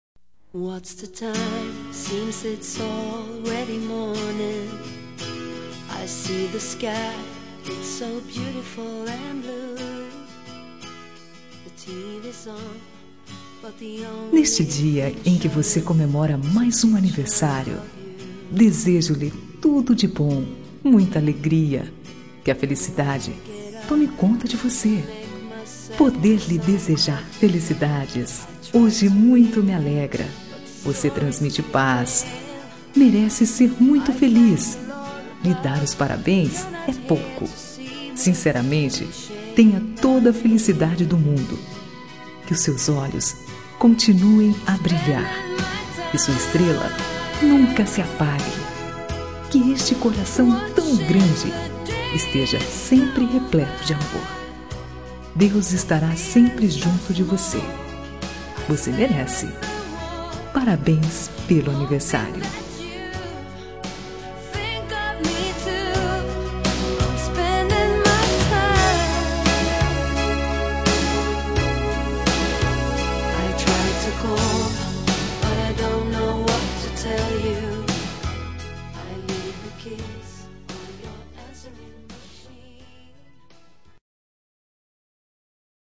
Telemensagem Aniversário de Amiga – Voz Feminina – Cód: 1534